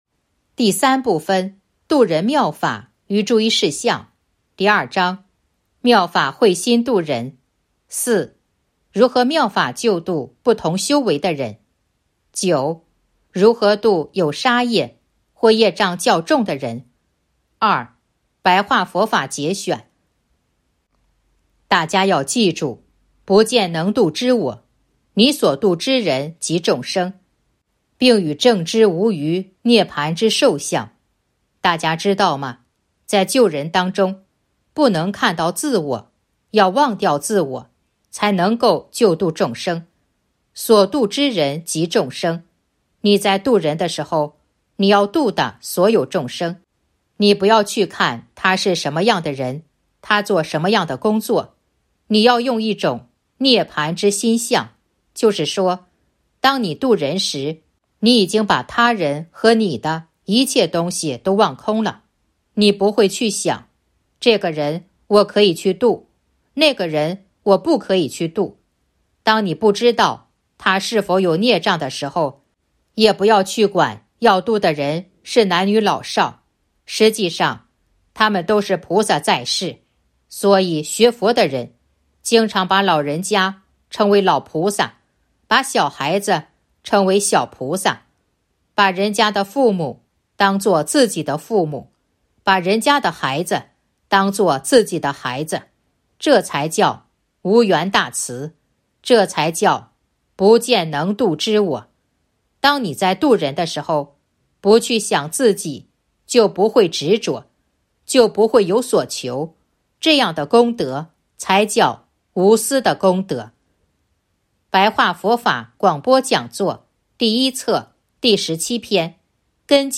038.（九）如何度有杀业或业障较重的人 2. 白话佛法节选《弘法度人手册》【有声书】